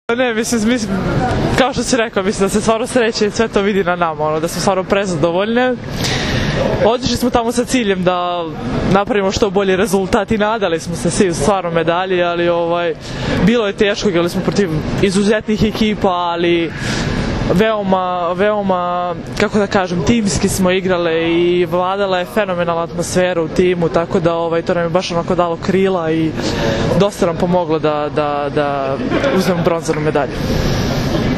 IZJAVA TIJANE MALEŠEVIĆ